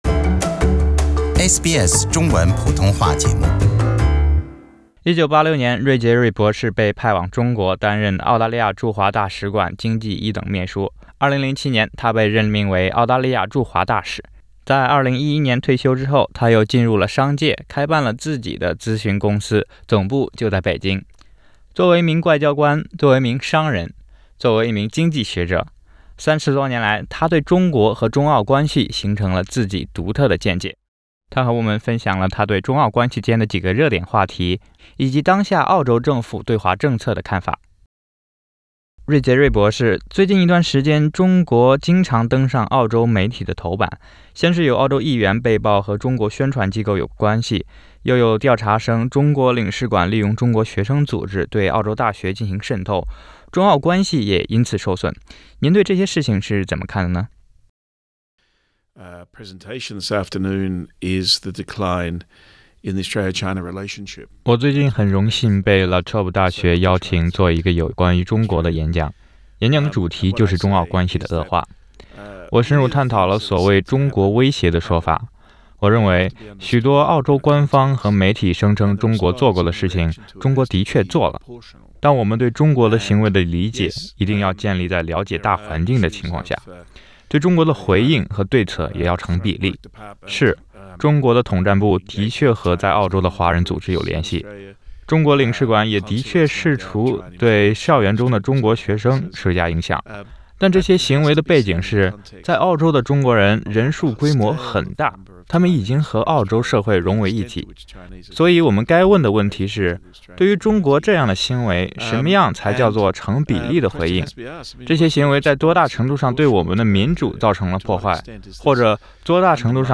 前驻华大使芮捷锐博士（Dr. Geoff Raby）接受SBS普通话节目采访，分享他对目前中国对澳洲施加影响、中澳关系热点问题和莫里森政府对华政策的看法。
澳大利亚前驻华大使芮捷锐（Geoff Raby）做客SBS演播室。